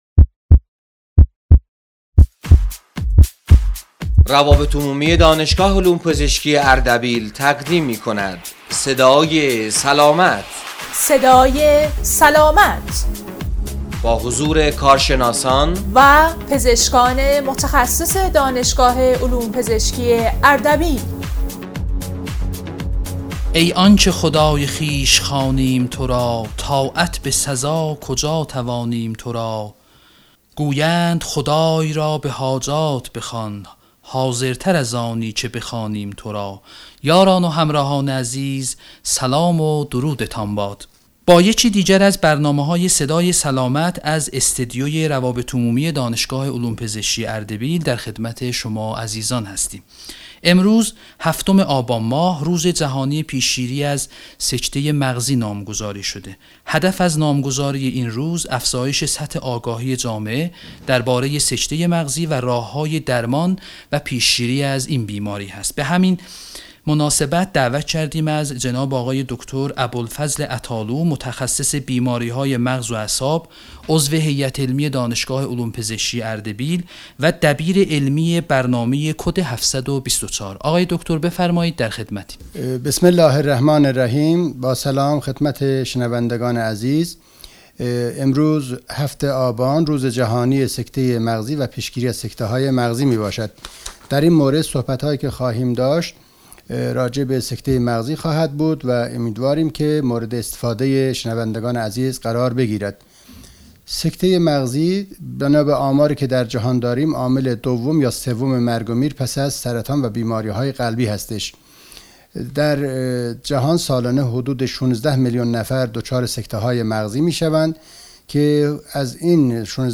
برنامه رادیویی صدای سلامت میهمان این برنامه